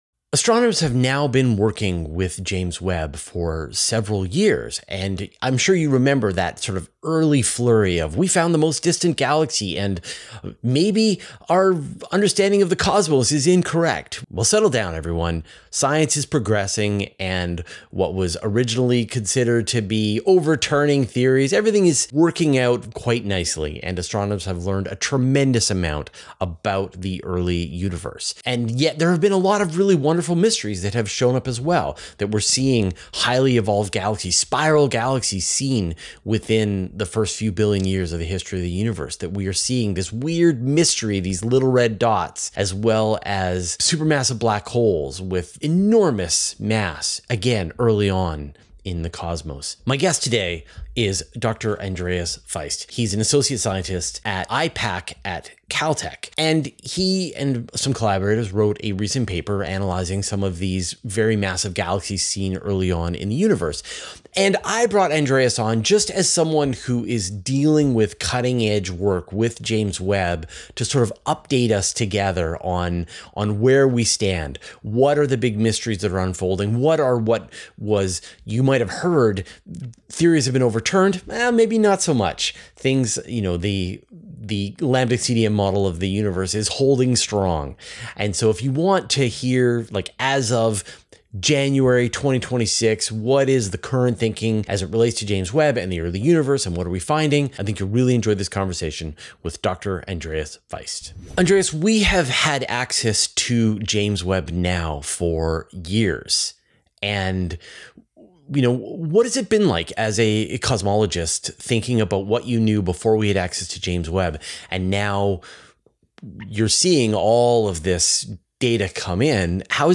Finding out in this interview.